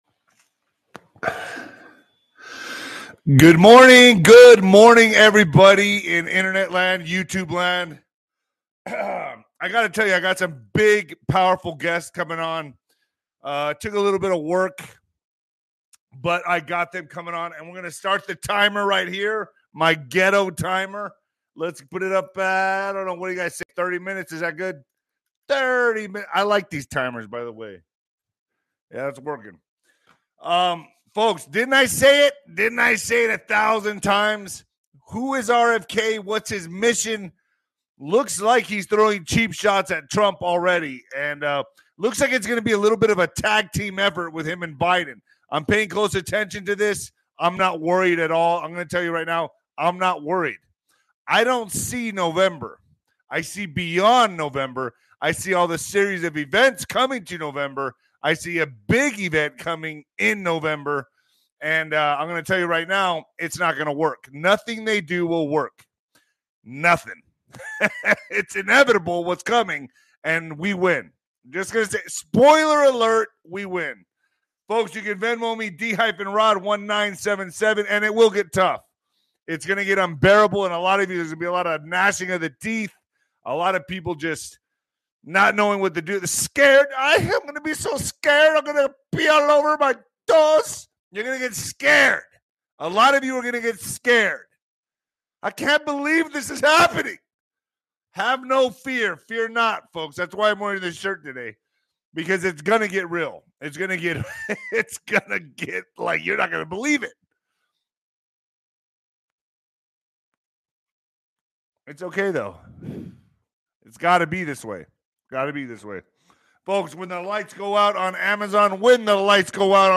Don't miss our exclusive interview with a former Green Beret.